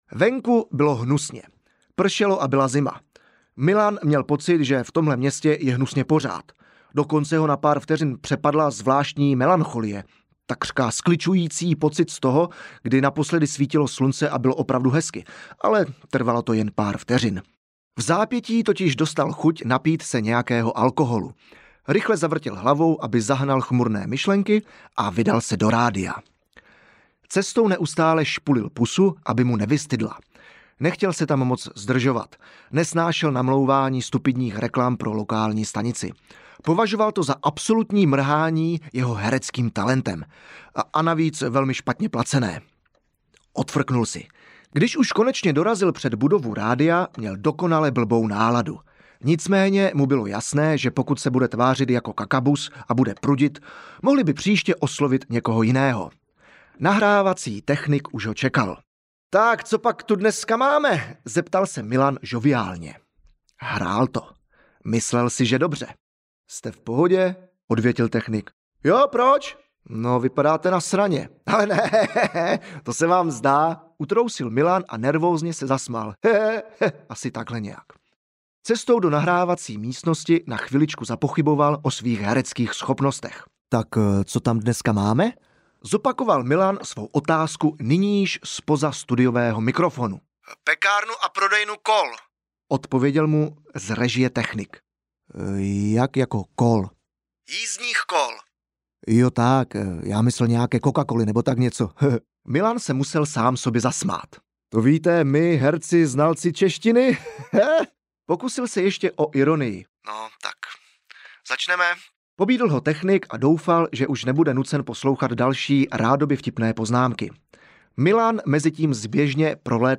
Herec k nepřežitı́ audiokniha
Ukázka z knihy